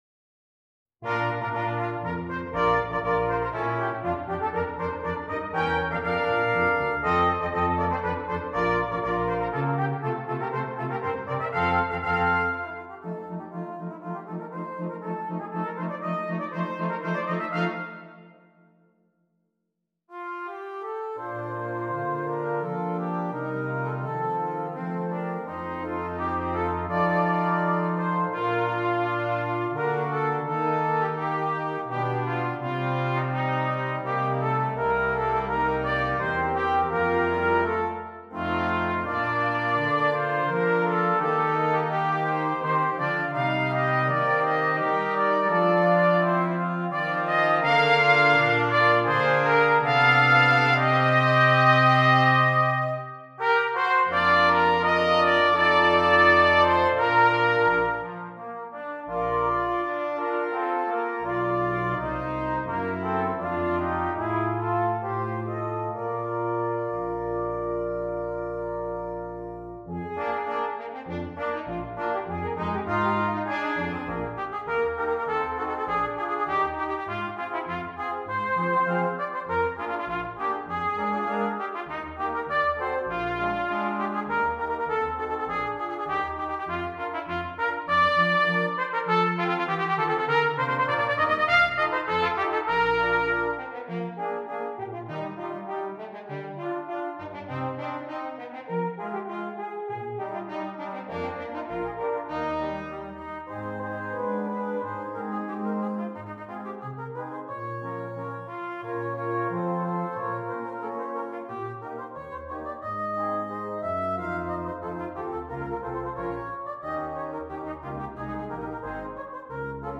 Brass Quintet
This fun light piece is a great showpiece for the cornet.